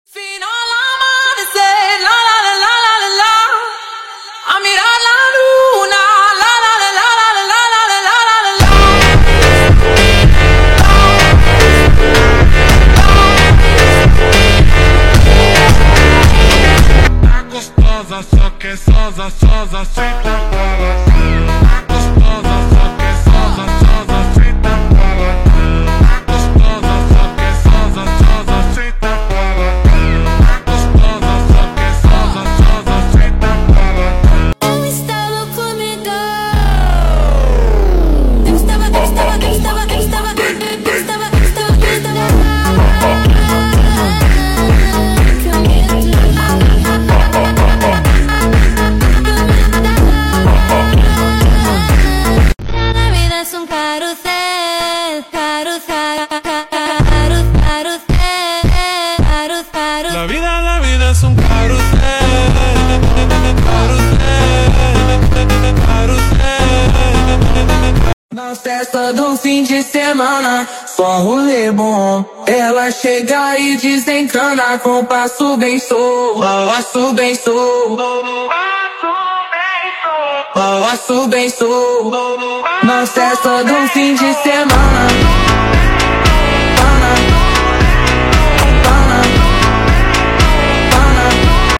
phonk/funk songs